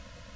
Index of /90_sSampleCDs/Roland LCDP09 Keys of the 60s and 70s 1/ORG_Farfisa Flts/ORG_Farflut Noiz